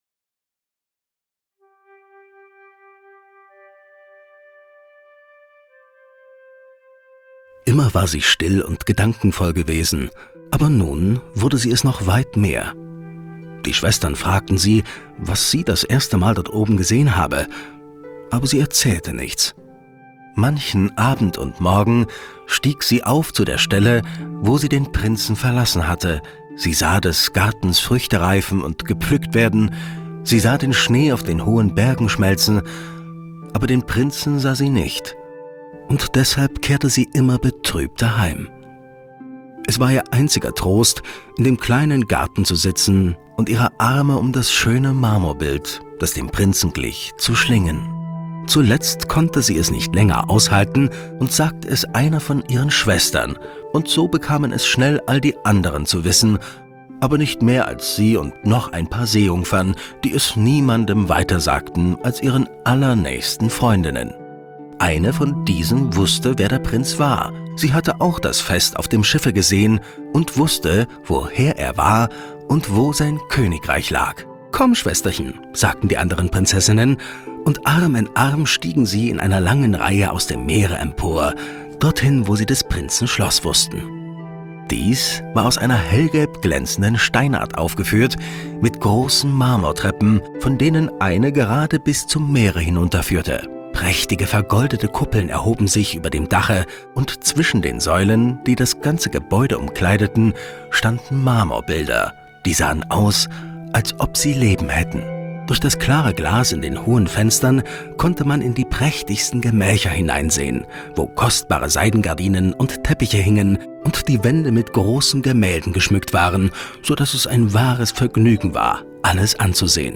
Die kleine Meerjungfrau: Hörbuch | Wunderhaus Verlag